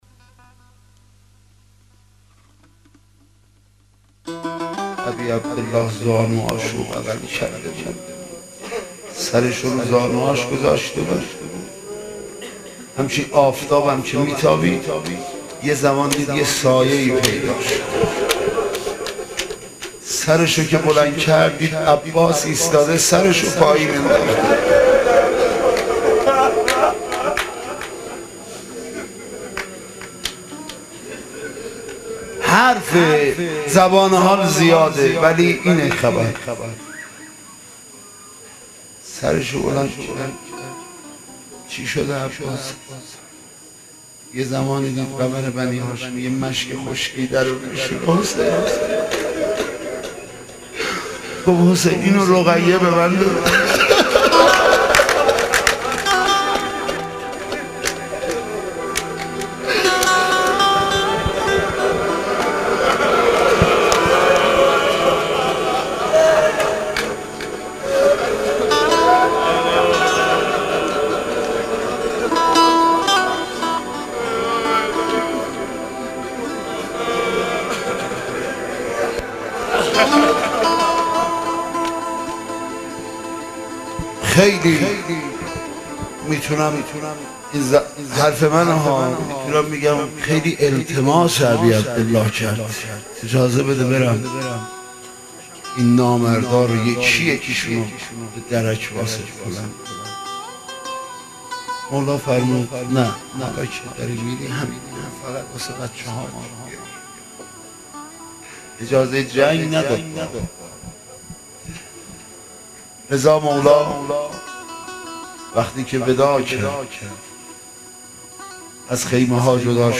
روضه قدیمی
روضه-حضرت-ابوالفضل-علیه-السلام.mp3